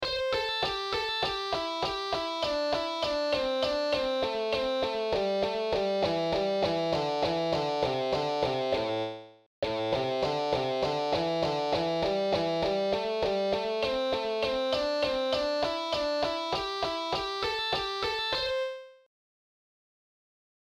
Blues Exercises > blues exercise
blues+exercise.mp3